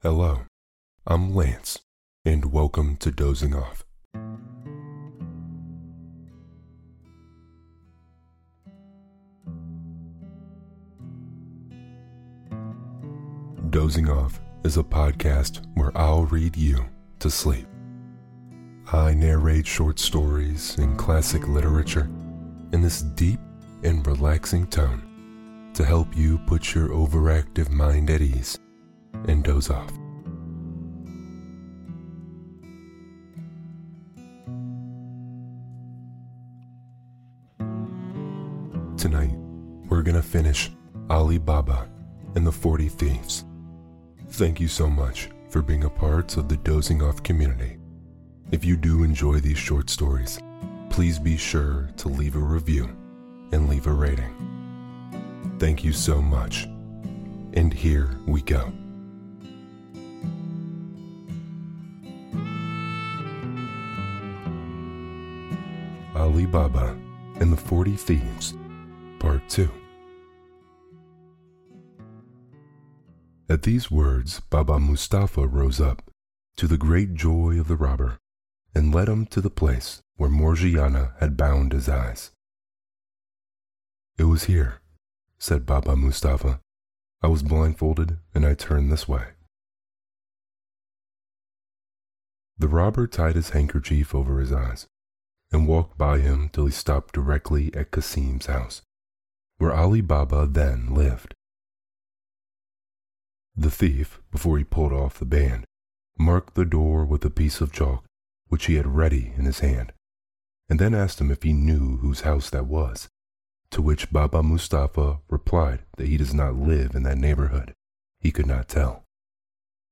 Peter Pan - J.M Barrie (Part Four) – Dozing Off | Deep Voice ASMR Bedtime Stories – Lyssna här – Podtail